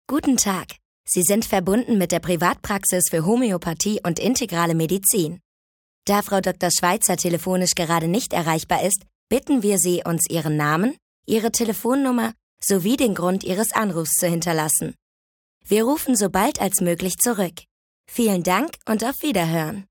Zusammenschnitt Nachrichten und Werbung